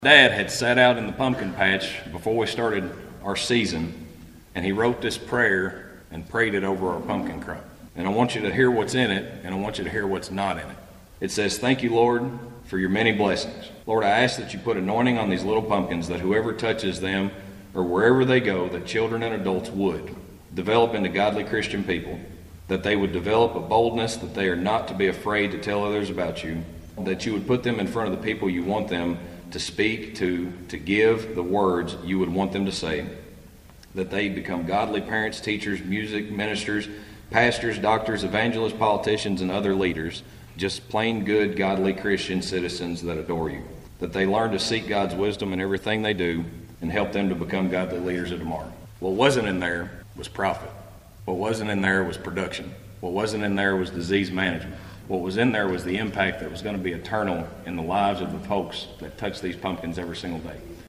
Agriculture Commissioner Shell Keynote Speaker At Christian County Salute To Agriculture Breakfast